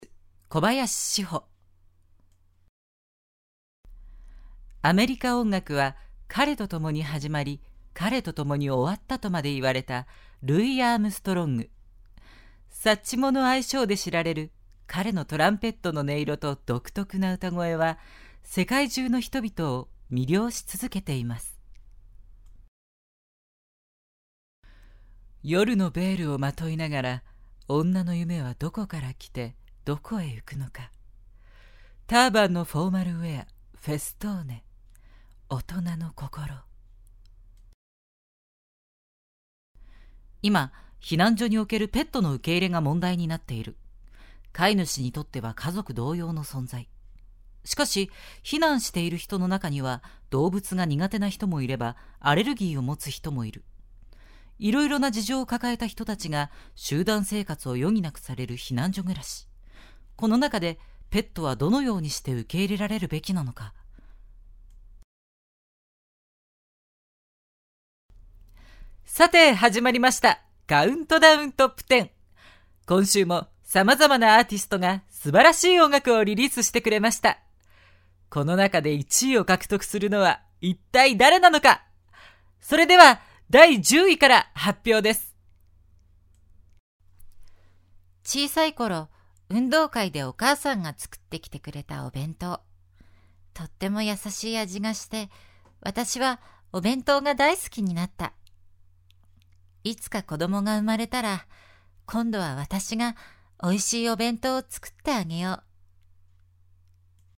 ボイスサンプル
ナレーション
narration.mp3